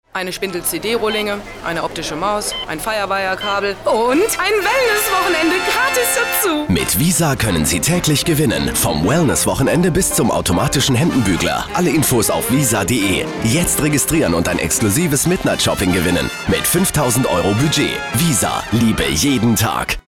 rauchige, knarzig-raue, sehr tief gehende Blues-Stimme, gut für Comic/Kunstfiguren (Lenya / Knef), englisch fliessend außergewöhnliches, mitreissendes Lachen, Kunststimmen und Imitation von Dialekten
Sprechprobe: eLearning (Muttersprache):
female German voice over artist, deep and rough voice preferably comics, video-games, strange characters. blues-singer. English fluent